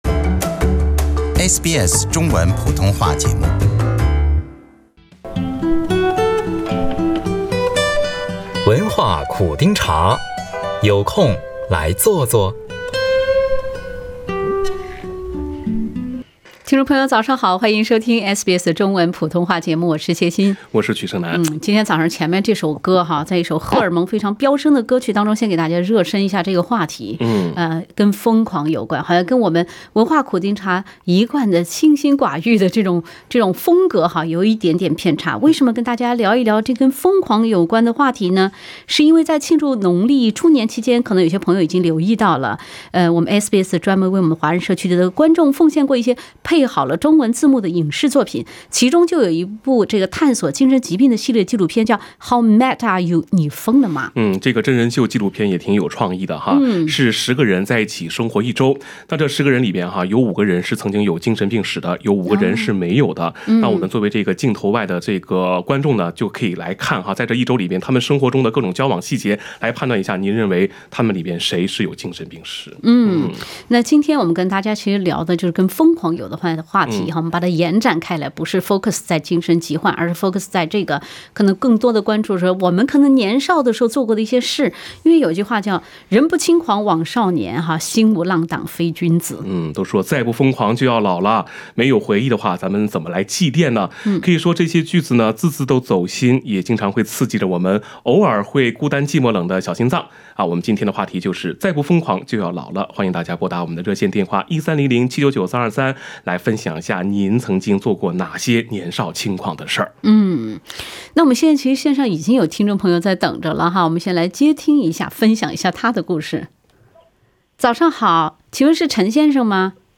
本期文化苦丁茶热线节目《再不疯狂就老了》，主持人与听众一起分享那些年的年少轻狂之事。